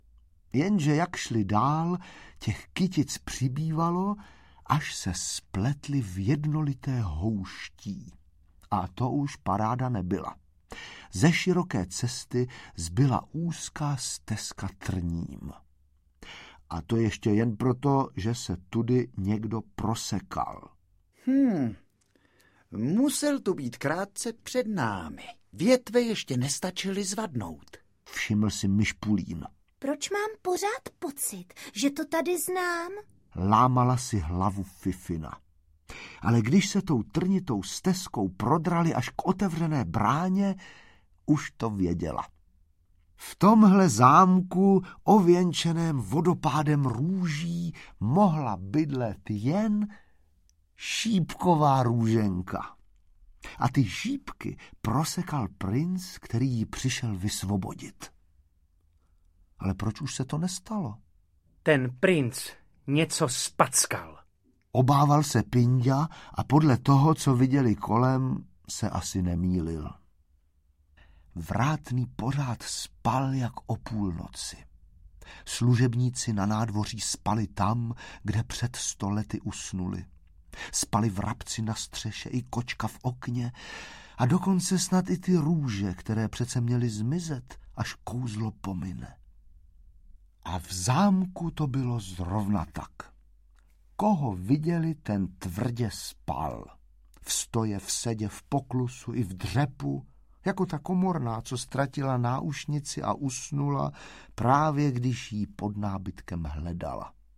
Čtyřlístek v pohádce audiokniha
Ukázka z knihy
• InterpretTomáš Hanák, Milan Šteindler, Václav Vydra ml., Magdalena Reifová